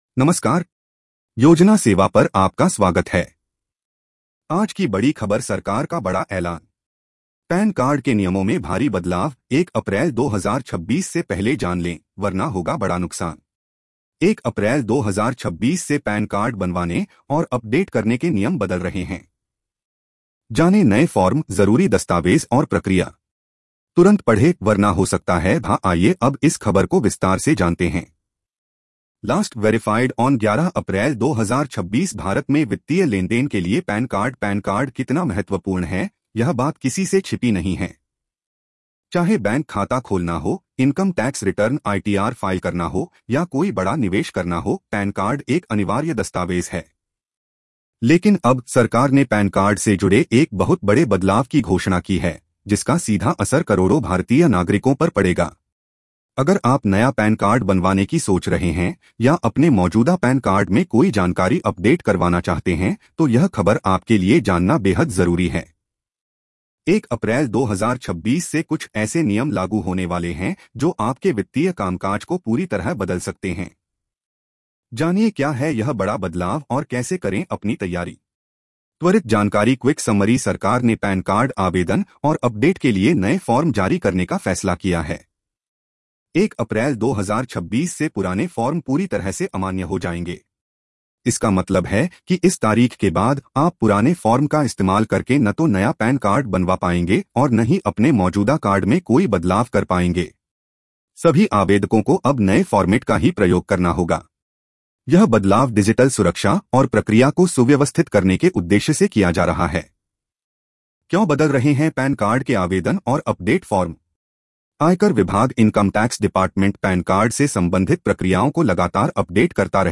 News Audio Summary
🎧 इस खबर को सुनें (AI Audio):